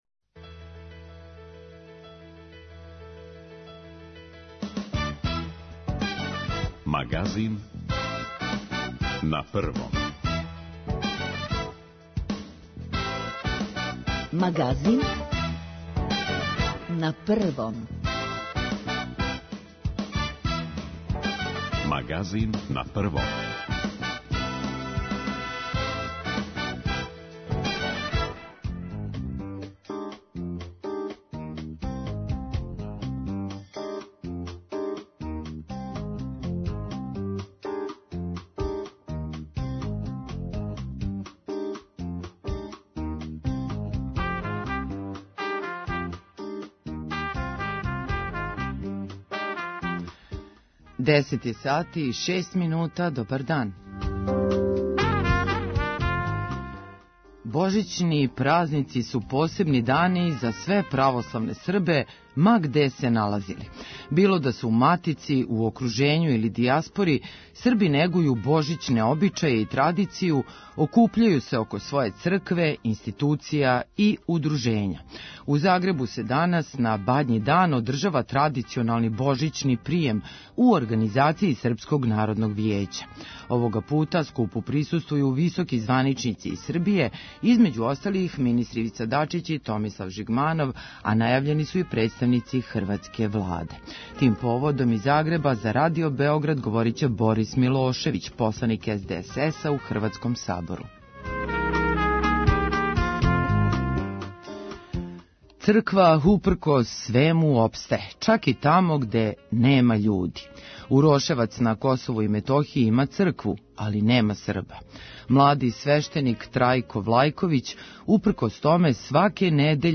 Тим поводом из Загреба за Радио Београд говори Борис Милошевић, посланик у хрватском Сабору